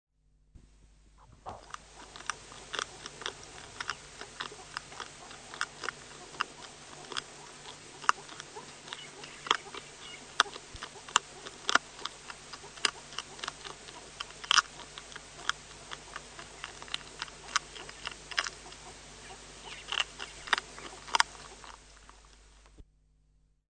Звук кражи клеща или скорпиона сурикатом